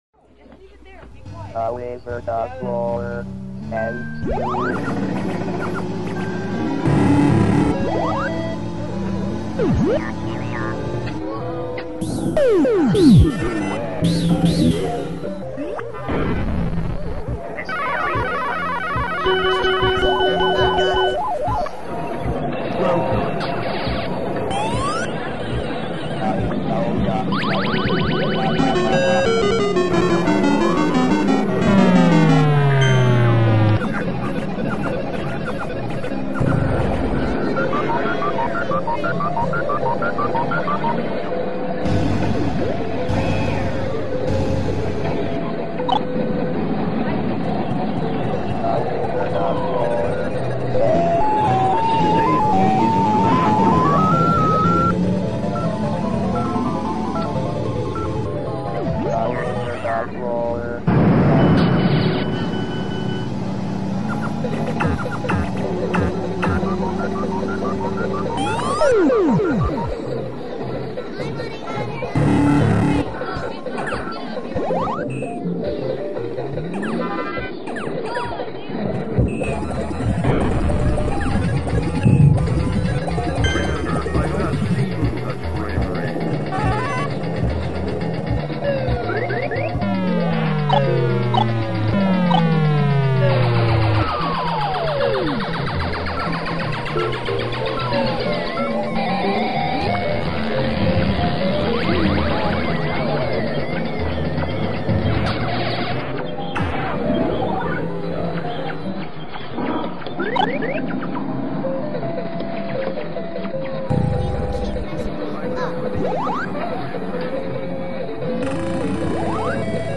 ambientsound.mp3